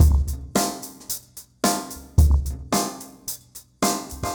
RemixedDrums_110BPM_27.wav